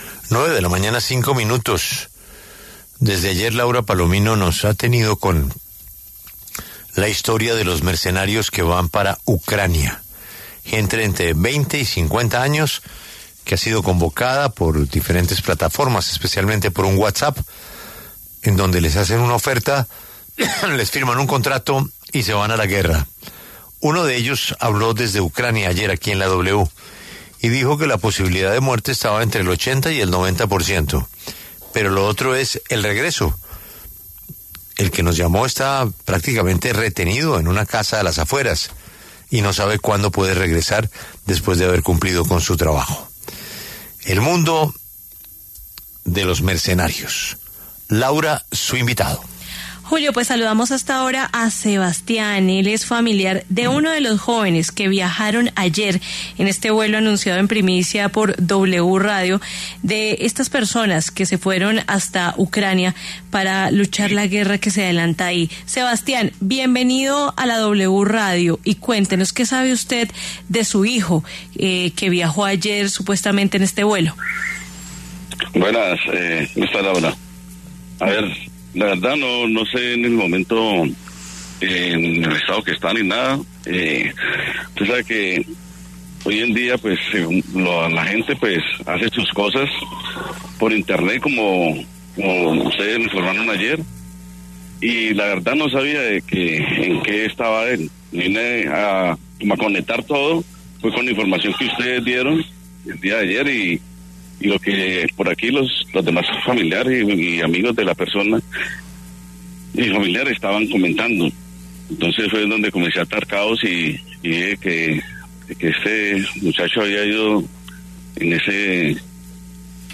Habla padre de joven mercenario que viajó a Ucrania